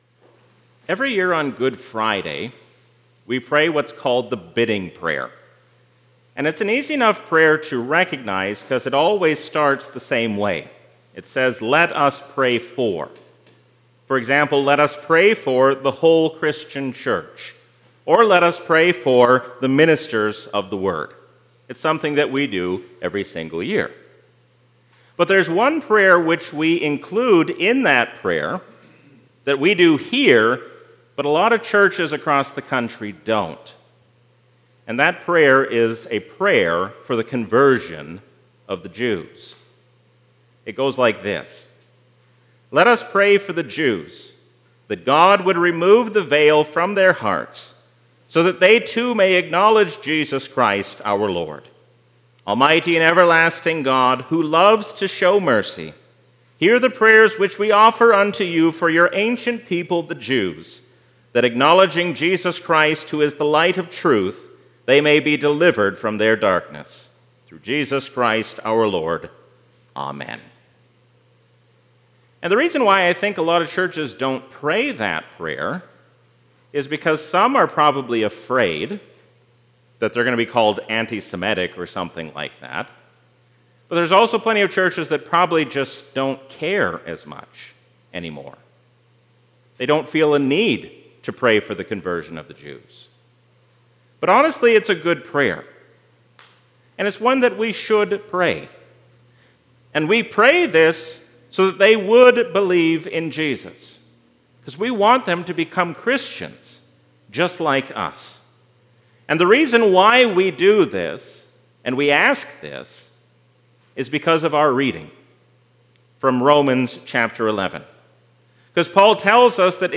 A sermon from the season "Trinity 2022." When we humble ourselves under the hand of God, then we have no reason to be anxious about the future.